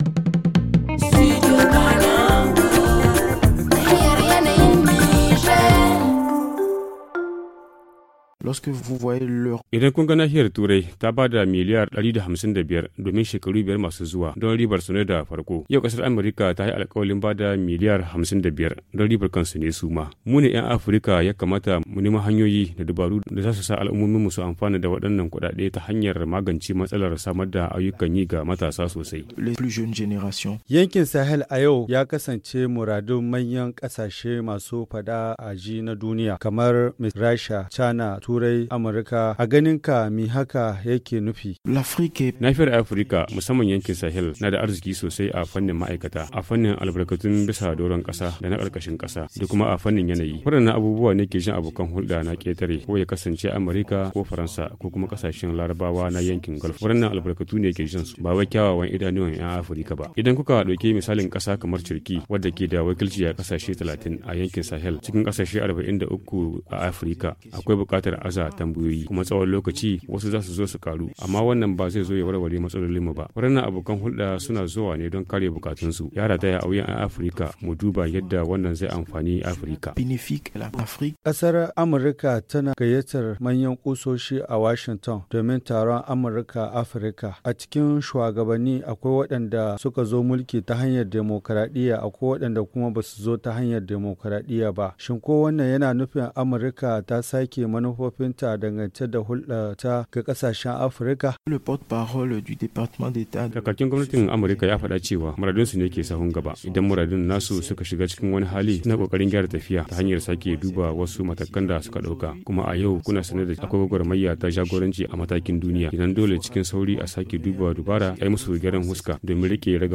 Il a été interrogé à Washington